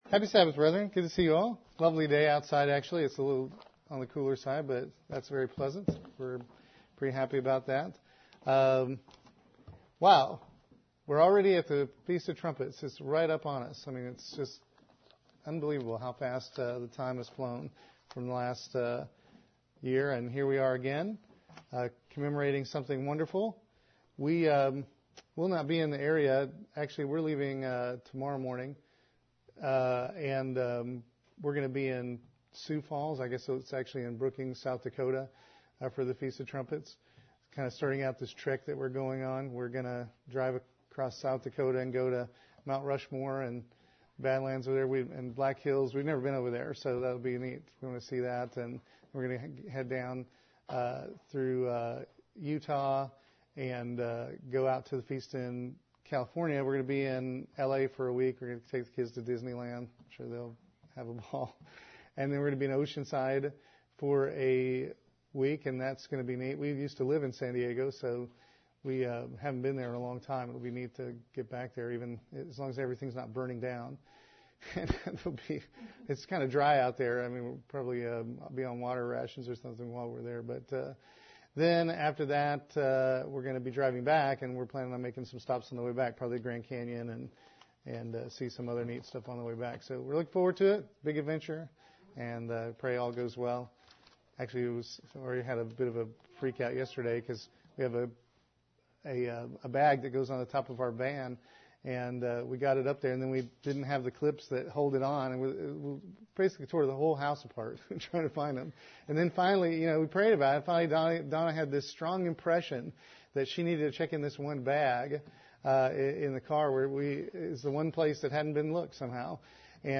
Given in Columbia - Fulton, MO
Print Ten spiritually meaningful points about the Feast of Trumpets UCG Sermon Studying the bible?